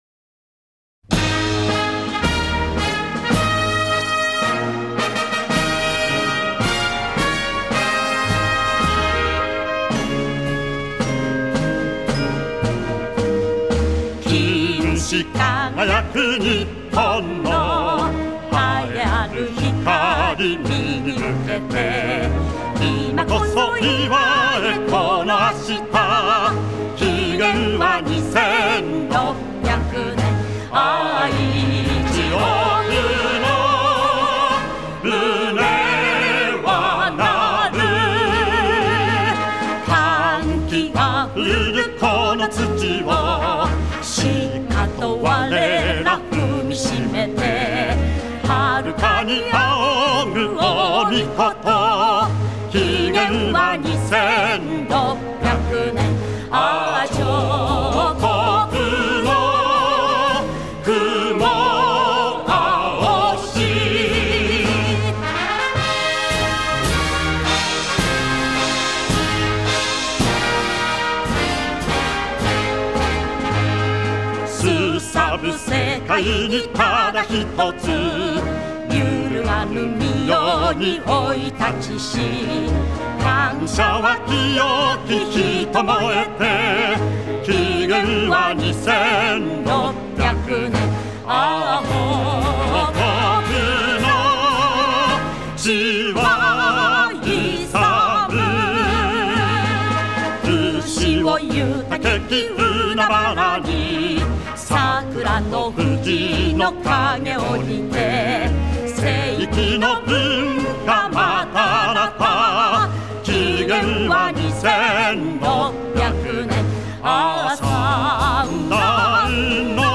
А вот красивый марш, в который включен и женский вокал.
Для яп. военных маршей это не очень характерно.